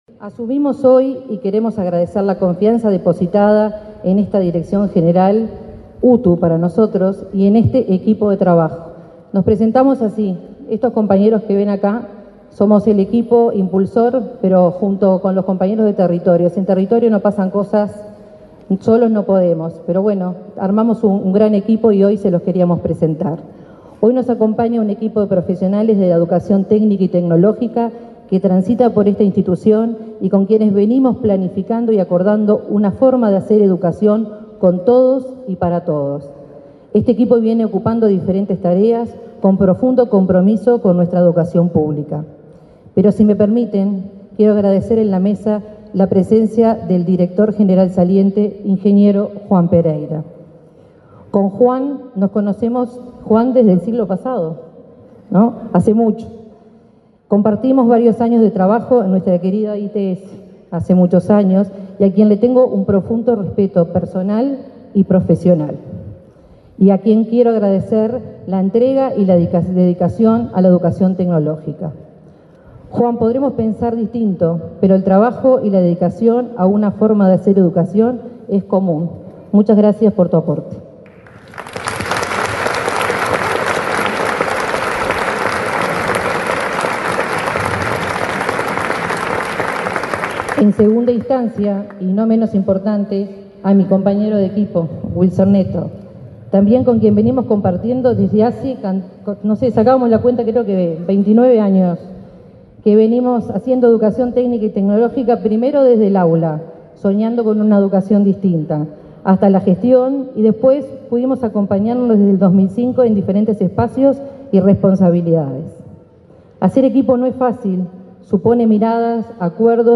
Durante el acto, se expresaron, además de Verderese, el presidente de la Administración Nacional de Educación Pública, Pablo Caggiani, y la subsecretaria de Educación y Cultura, Gabriela Verde.